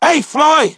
synthetic-wakewords
ovos-tts-plugin-deepponies_Franklin_en.wav